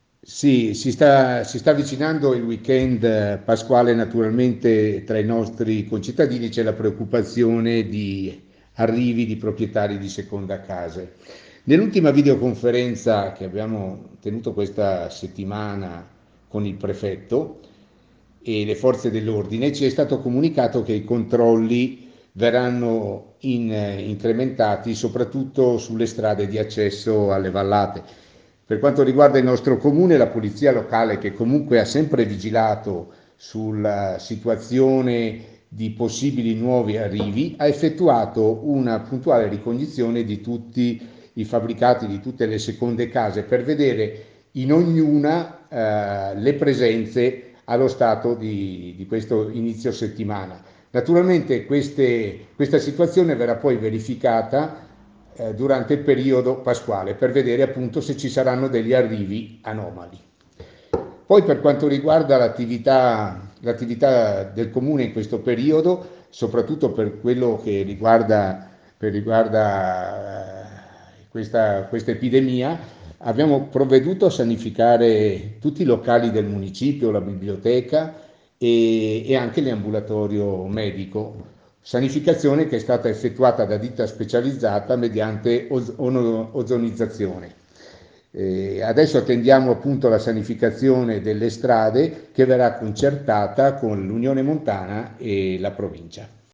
DAL GIORNALE RADIO DEL 7 APRILE